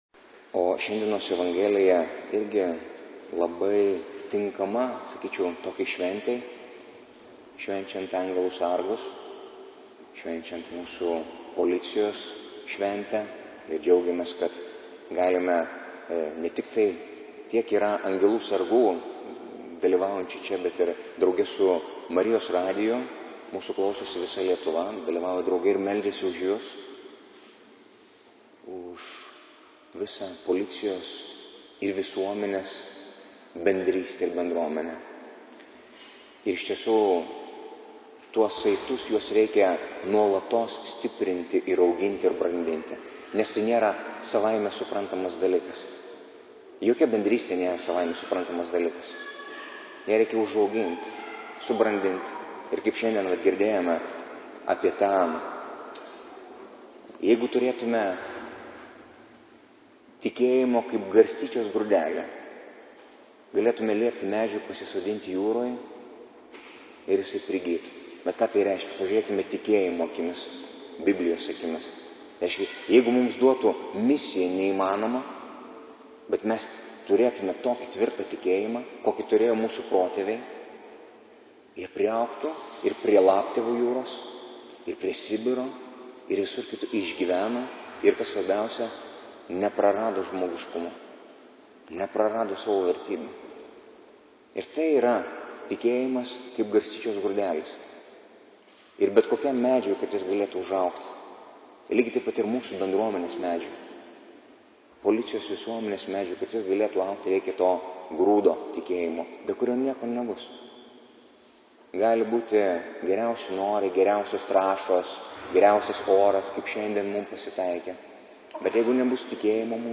Audio pamokslas Nr1: 2016-10-02-xxvii-eilinis-sekmadienis